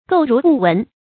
诟如不闻 gòu rú bù wén
诟如不闻发音